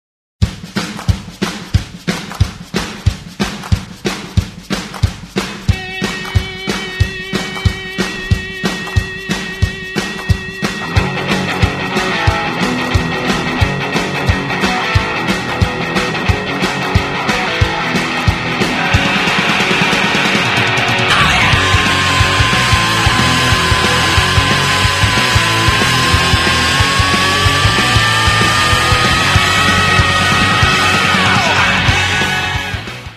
To me it's pretty straightforward hard rock.